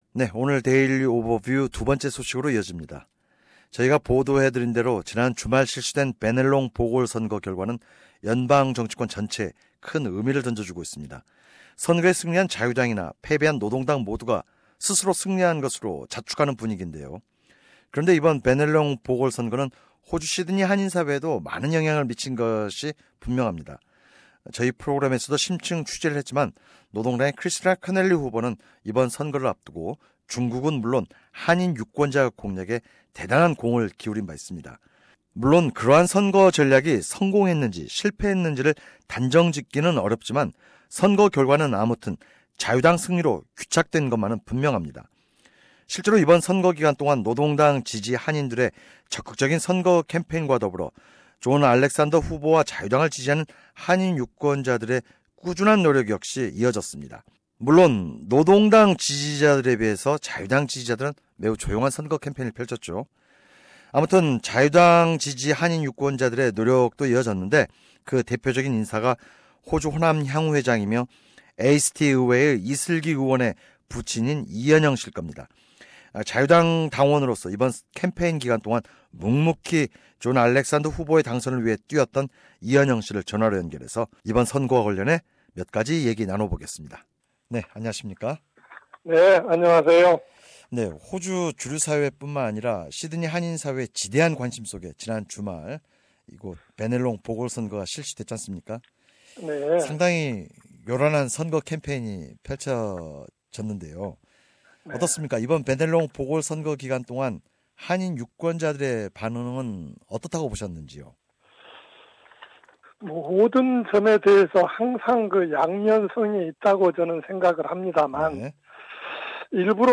[인터뷰 내용은 오디오 뉴스를 통해 접하실 수 있습니다.]